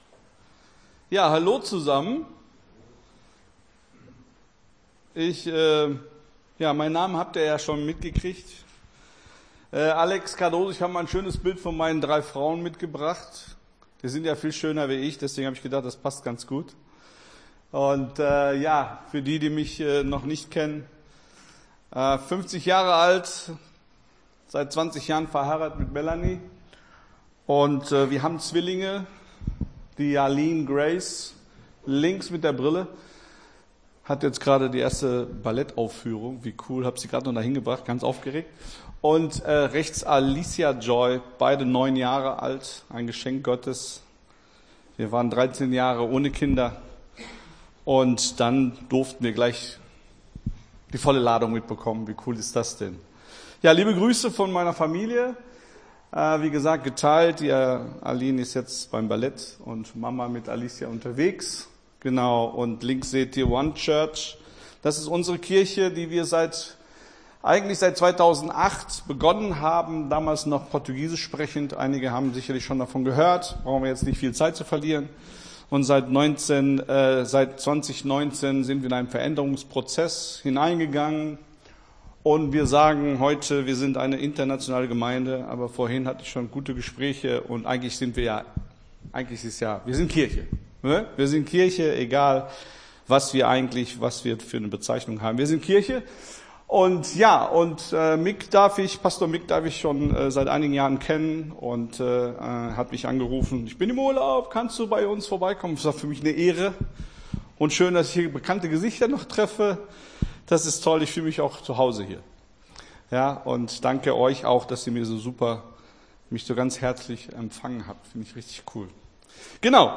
Gottesdienst 11.09.22 - FCG Hagen